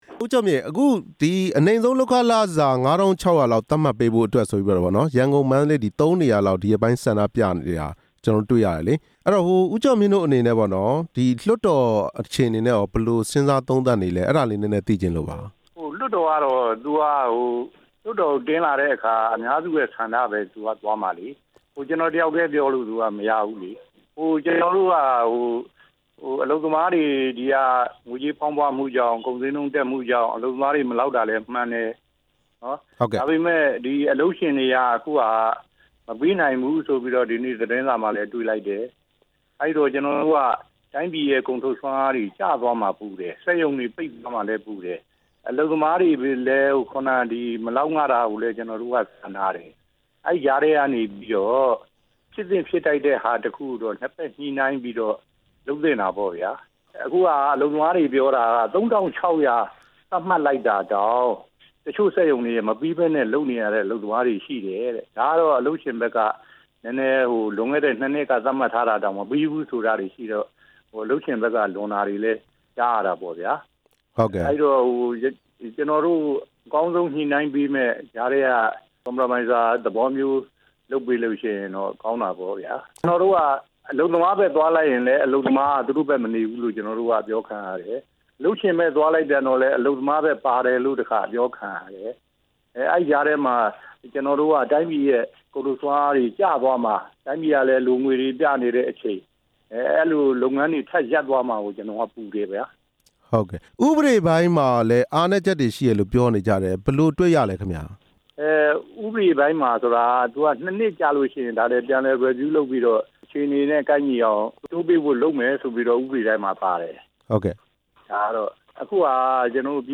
အနိမ့်ဆုံးလုပ်ခနှုန်းထားအကြောင်း မေးမြန်းချက်